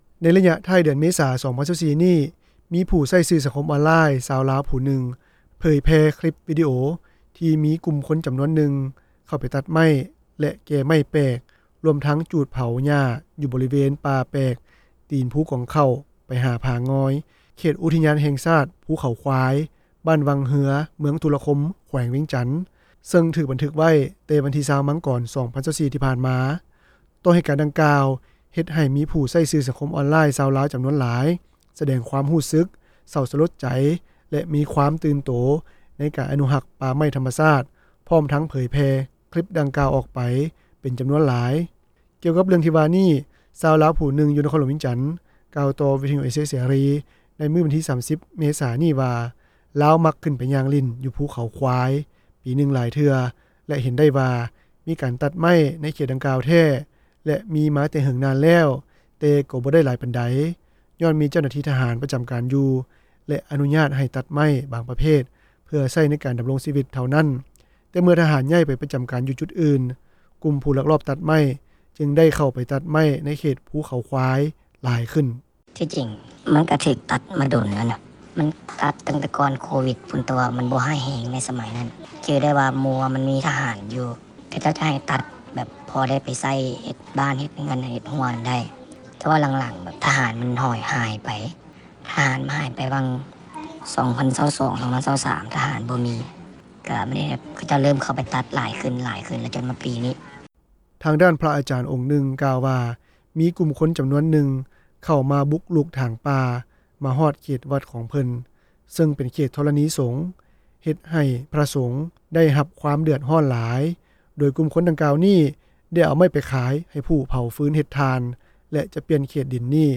ກ່ຽວກັບເລື່ອງທີ່ວ່ານີ້, ຊາວລາວ ຜູ້ນຶ່ງ ຢູ່ນະຄອນຫຼວງວຽງຈັນ ກ່າວຕໍ່ວິທຍຸເອເຊັຽເສຣີ ໃນມື້ວັນທີ 30 ເມສາ ນີ້ວ່າ ລາວມັກຂຶ້ນໄປຢ່າງຫຼິ້ນ ຢູ່ພູເຂົາຄວາຍ ປີໜຶ່ງຫຼາຍເທື່ອ ແລະ ເຫັນໄດ້ວ່າ ມີການຕັດໄມ້ ໃນເຂດດັ່ງກ່າວແທ້ ແລະ ມີມາແຕ່ເຫິງນານແລ້ວ, ແຕ່ກໍບໍ່ໄດ້ຫຼາຍປານໃດ ຍ້ອນມີເຈົ້າໜ້າທີ່ທະຫານ ປະຈຳການຢູ່ ແລະ ອະນຸຍາດ ໃຫ້ຕັດໄມ້ ບາງປະເພດ ເພື່ອໃຊ້ໃນການດຳລົງຊີວິດ ເທົ່ານັ້ນ. ແຕ່ເມື່ອທະຫານ ຍ້າຍໄປປະຈຳການ ຢູ່ຈຸດອື່ນ, ກຸ່ມຜູ້ລັກລອບຕັດໄມ້ ຈຶ່ງໄດ້ເຂົ້າໄປຕັດໄມ້ ໃນເຂດພູເຂົາຄວາຍ ຫຼາຍຂຶ້ນ.